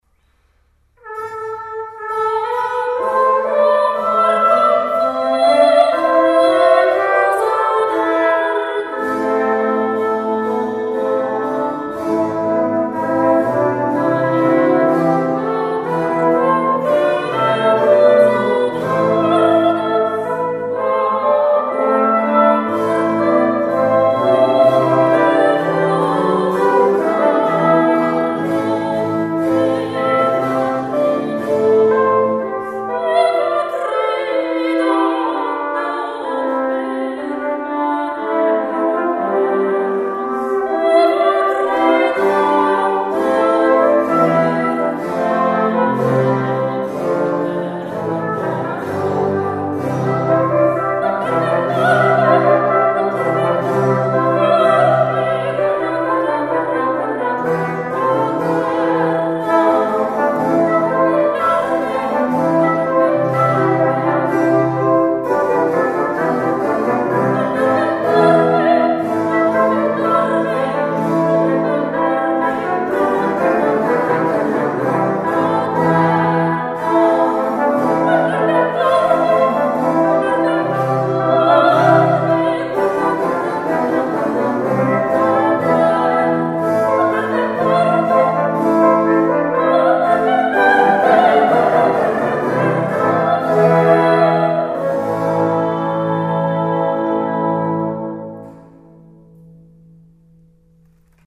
la registrazione è stata effettuata nel Maggio del 2011 nell'Oratorio dell’Annunziata di Piana Crixia (Savona).
Sono state utilizzate esclusivamente copie di strumenti rinascimentali.
L’eco è solo quella naturale dell'oratorio,